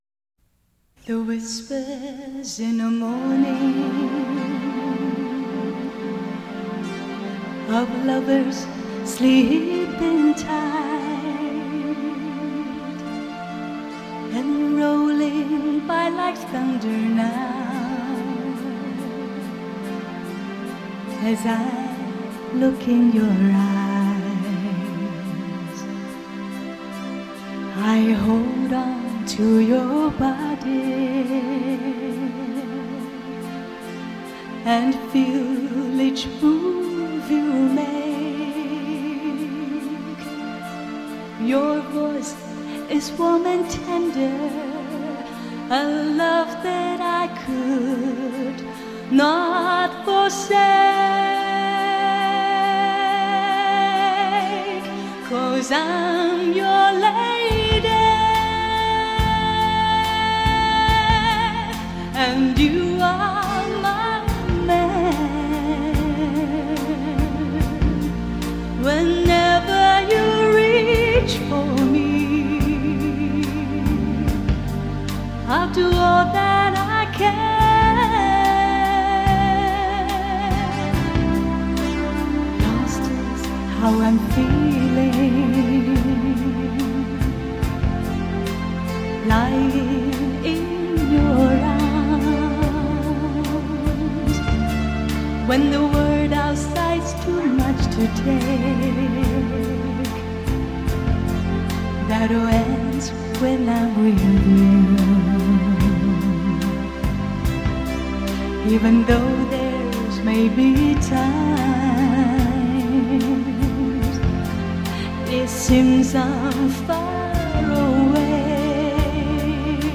她那高超的唱功、无与伦比的气息运用、丰沛的情感表达，无人能及。